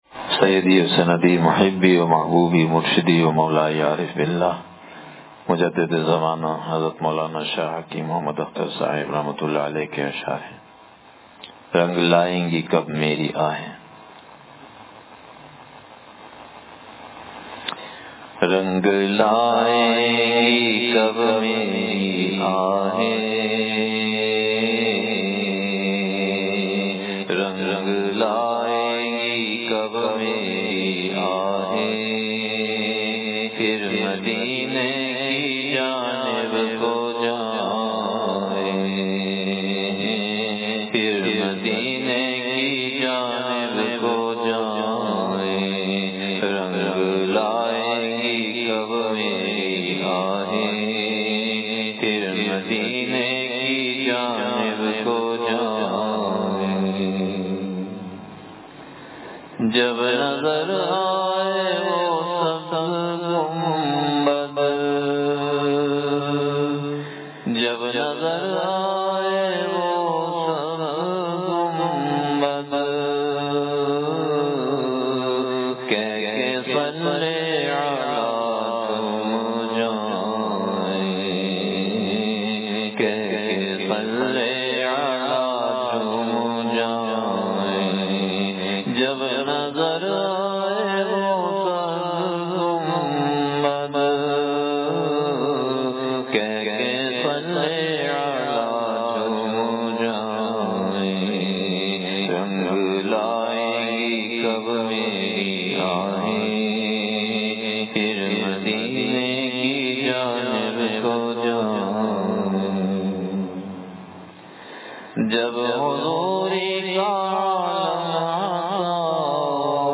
وعظ اللہ تعالی کی شانِ جذ ب (حصہ سوئم)